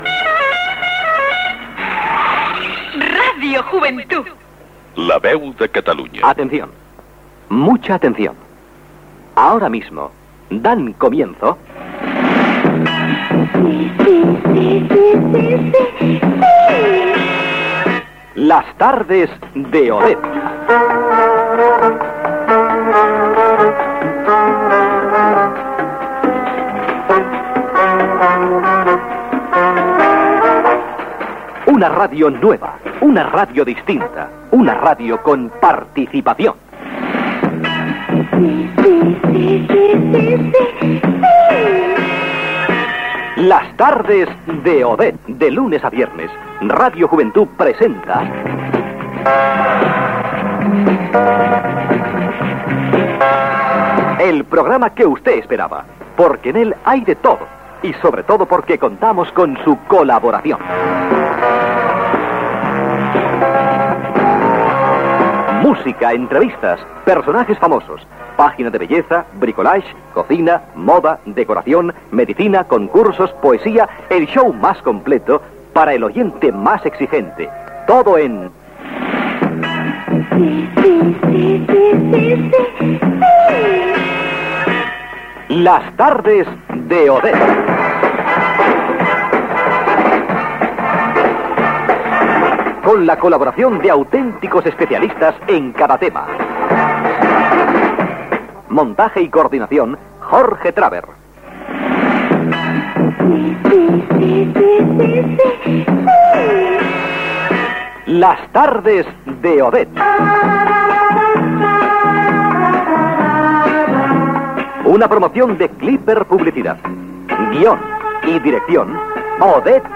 Indicatiu de l'emissora, careta d'entrada del programa